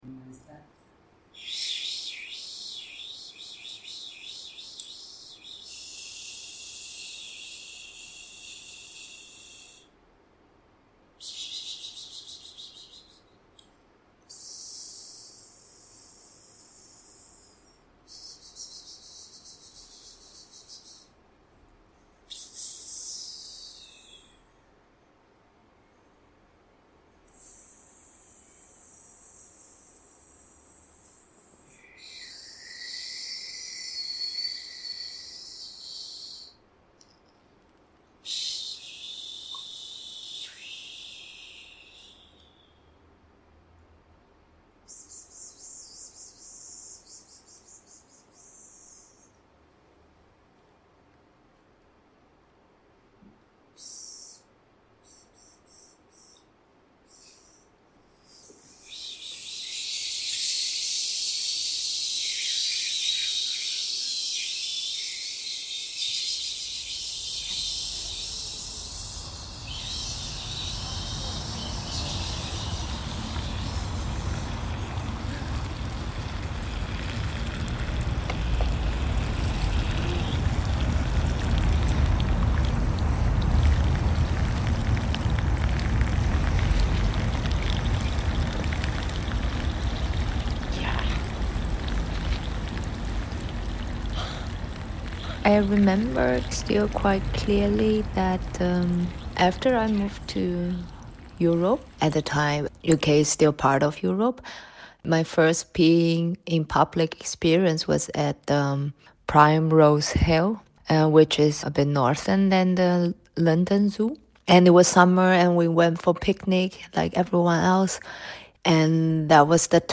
One afternoon in November, we gathered in an exhibition space to drink, to tell each other stories about peeing in public, to go outside and pee together among many weeds, and then to drink again, this time in a bar. In this audio piece, the waters from that event flow between stories told afterwards by some who attended and others who wanted to attend but could not. With partial anonymity, those who recognize the voices will be able to identify the authors of the stories.